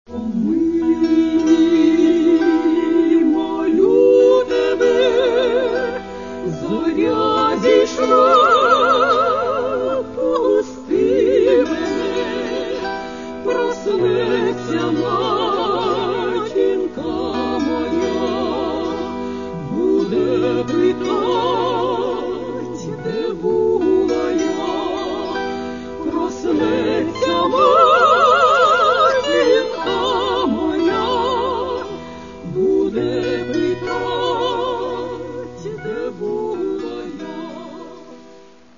music: folk song